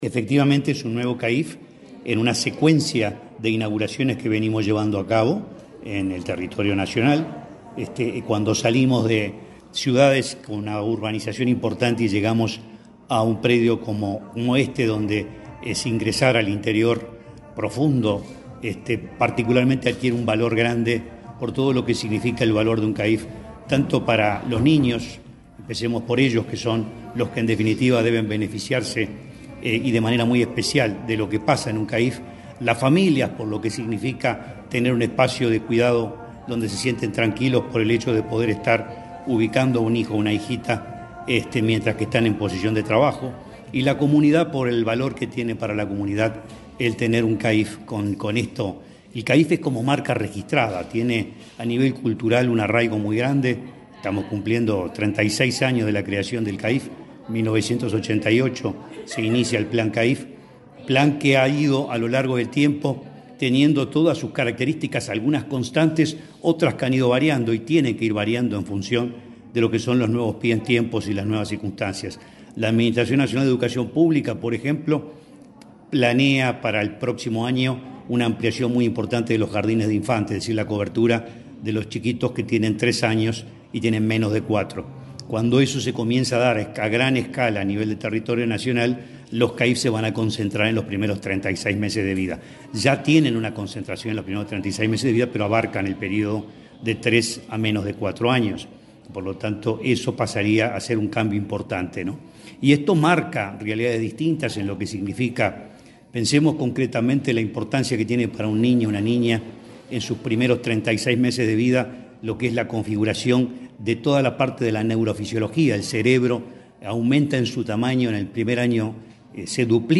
Declaraciones del presidente del INAU, Guillermo Fosatti
Declaraciones del presidente del INAU, Guillermo Fosatti 04/09/2024 Compartir Facebook X Copiar enlace WhatsApp LinkedIn El presidente del INAU, Guillermo Fosatti, dialogó con la prensa, antes de participar de la inauguración de una Centro de Atención a la Infancia y la Familia (CAIF) en Villa Constitución, departamento de Salto.